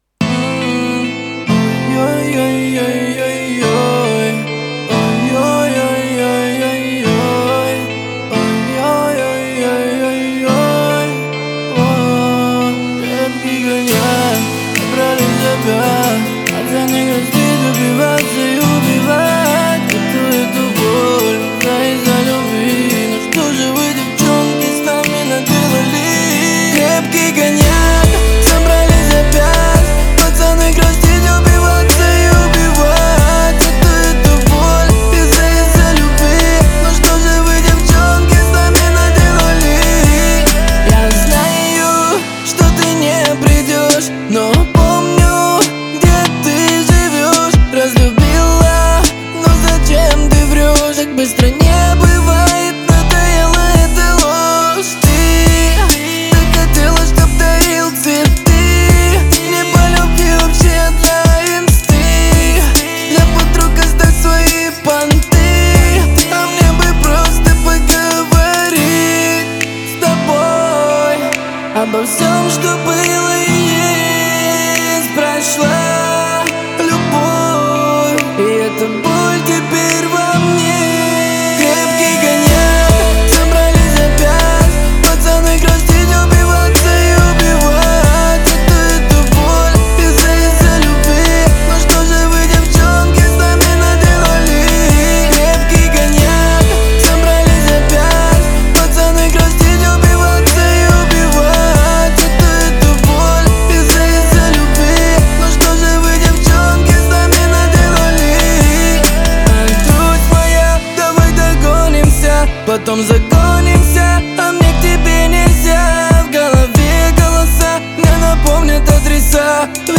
это яркий образец русского шансона